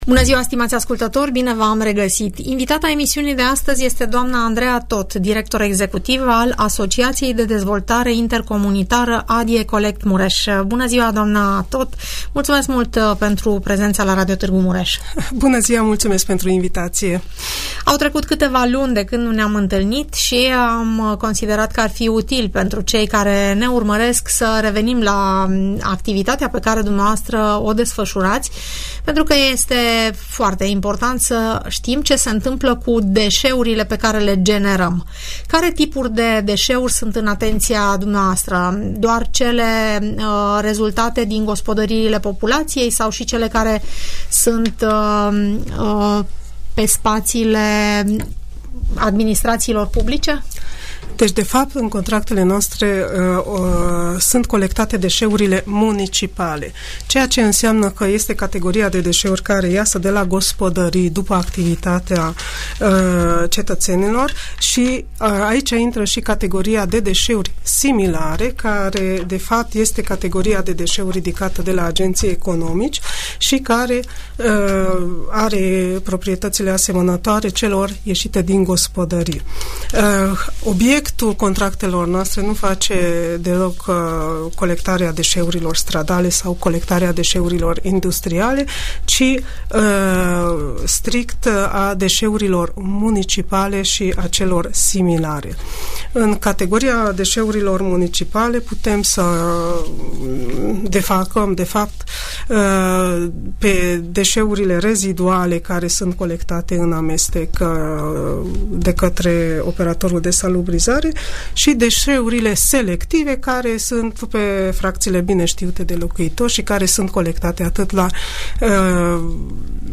Urmărește discuția pe această temă în emisiunea "Părerea ta" de la Radio Tg. Mureș